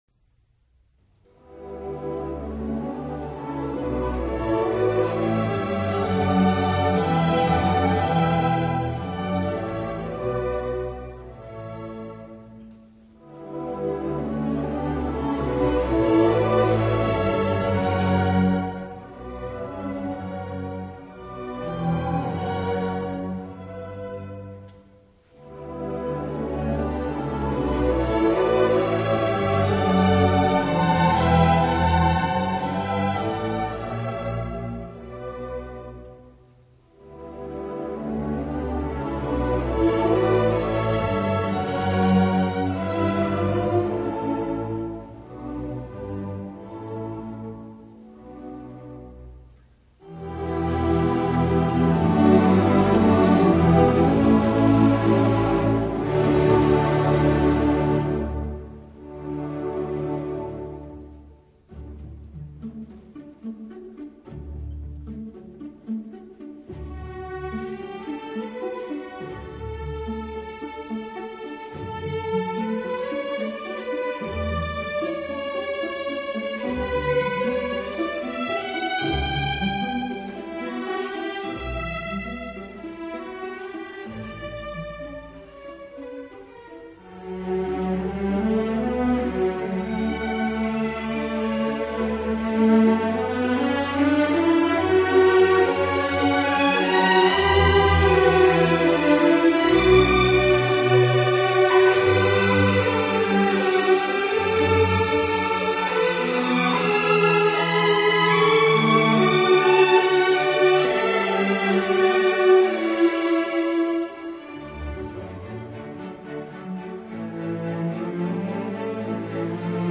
Ensemble de cordes